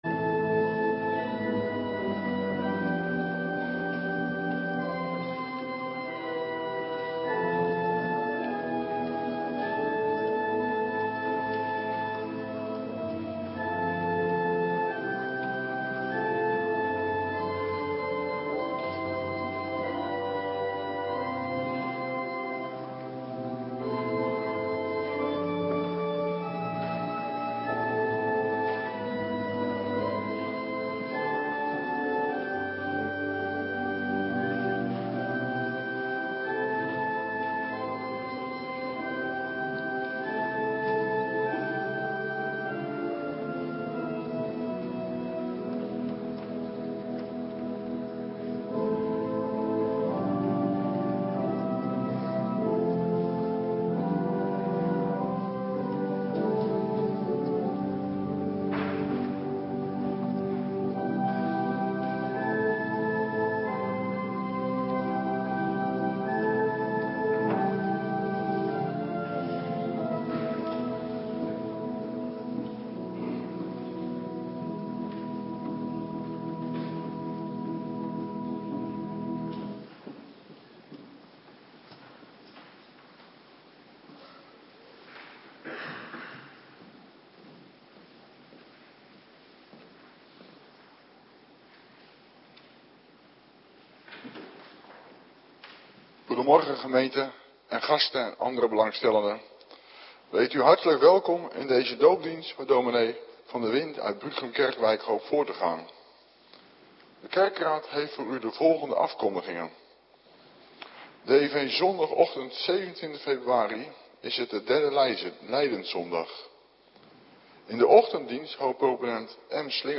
Morgendienst Heilige Doop - Cluster 2
Locatie: Hervormde Gemeente Waarder